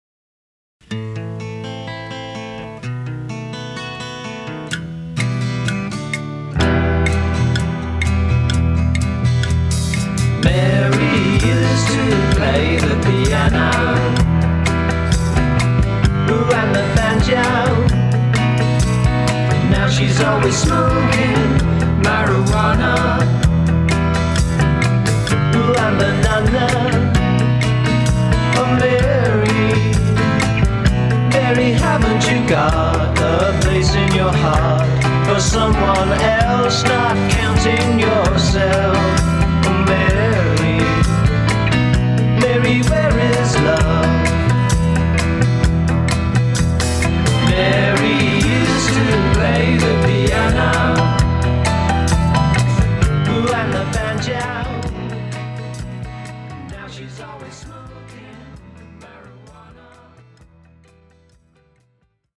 vocals, guitar
backing vocals, guitar
Recorded in London, 1973/74